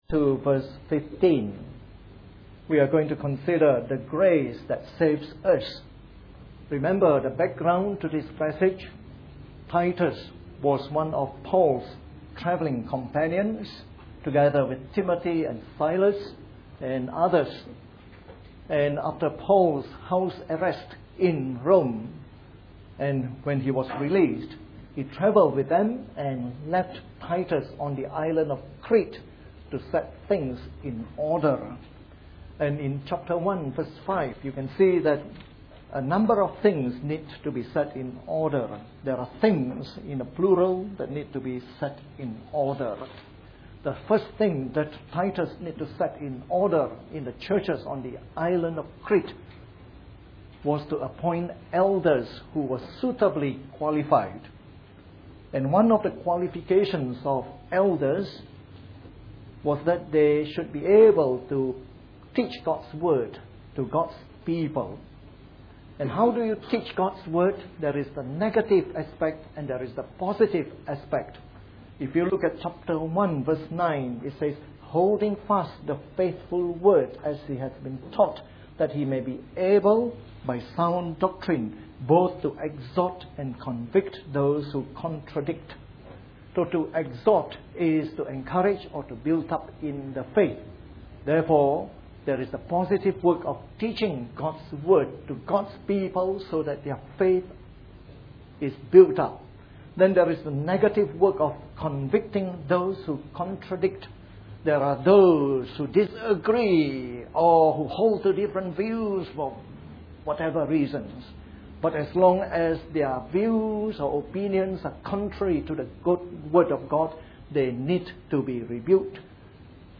A sermon in the morning service from our series on Titus.